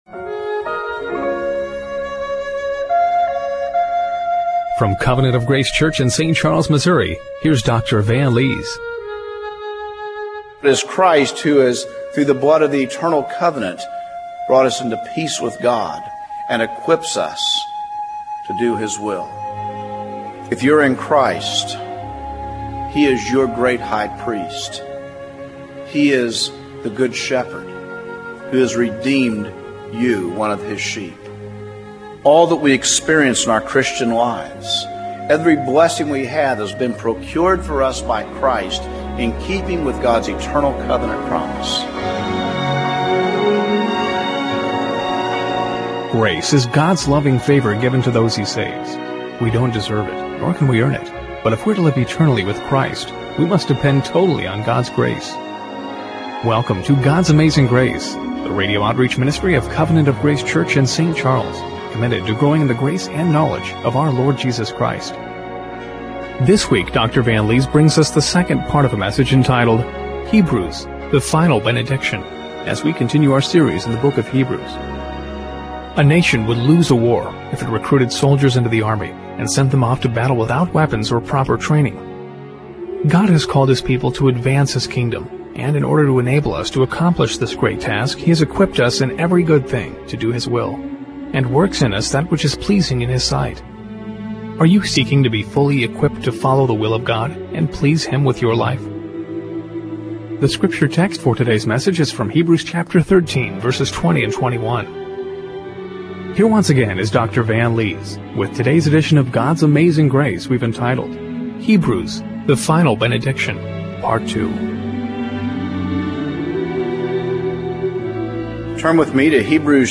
Hebrews 13:20-21 Service Type: Radio Broadcast Are you seeking to be fully equipped to follow the will of God and please Him with your life?